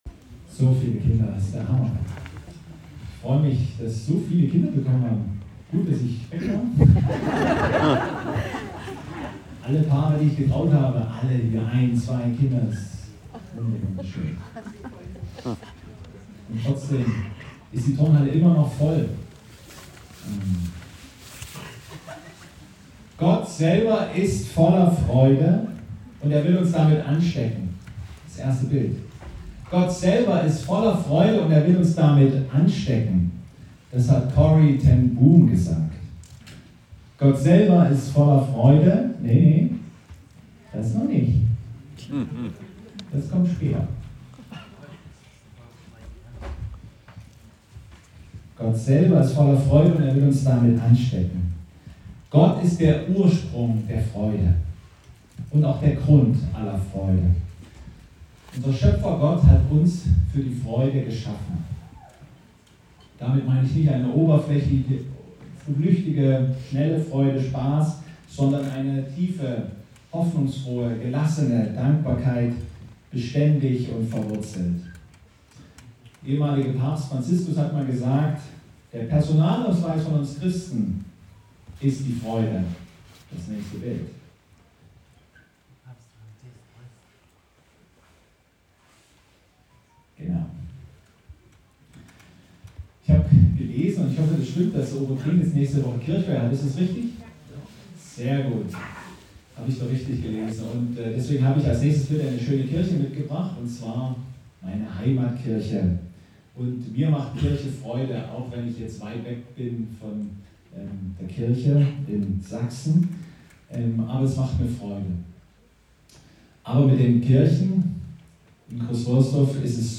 Gottesdienstart: Allianz-Gottesdienst